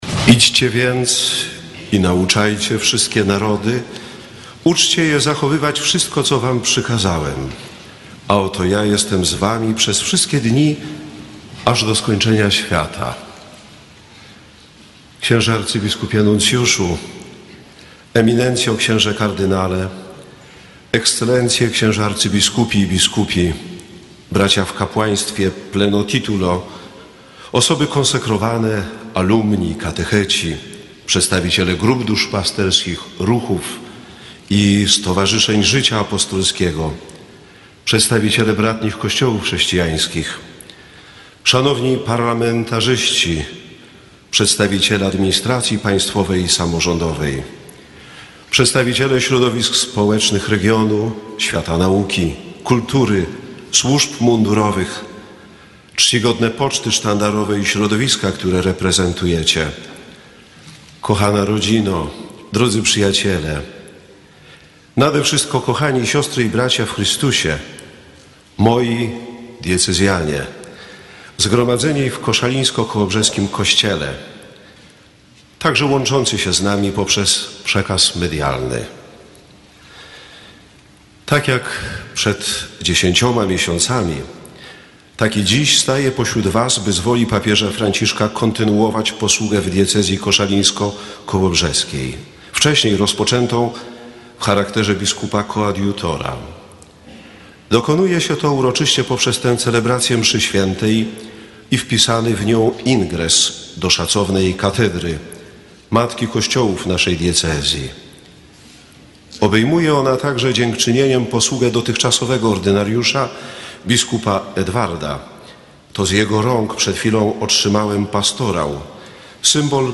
W pierwszych słowach homilii wygłoszonej 4 marca w koszalińskiej katedrze bp Zbigniew Zieliński nawiązał do polecenia Chrystusa zawartego w jego biskupim zawołaniu „Ut unum sint” – to znaczy „aby byli jedno”.
Posłuchaj całości homilii bp. Zbigniewa Zielińskiego /files/media/pliki/homilia ZZ ingres 92.mp3